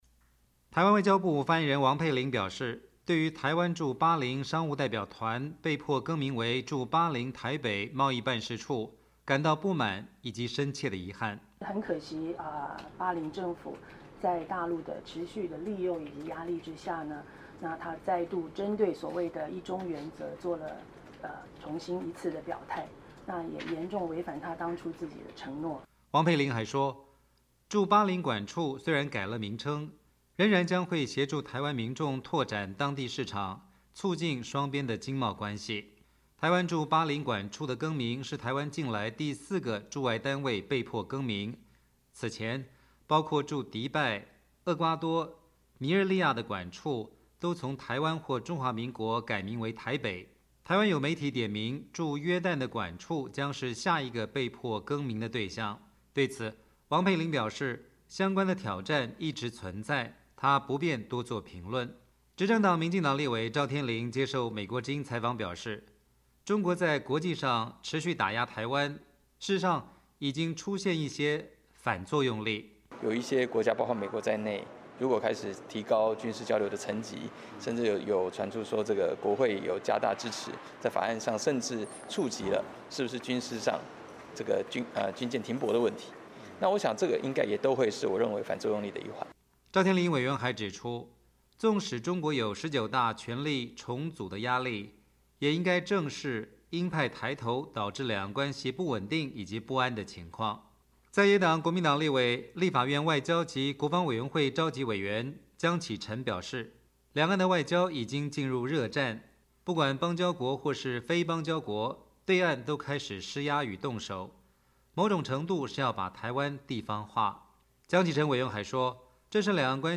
执政党民进党立委赵天麟接受美国之音采访表示，中国在国际上持续打压台湾，事实上已经出现一些反作用力。
在野党时代力量立委徐永明接受美国之音采访表示，巴林馆处被迫更名，应该不会是最后一个，这是北京系统性地改变台湾驻外单位名称的政策。